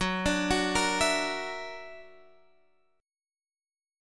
F#+7 Chord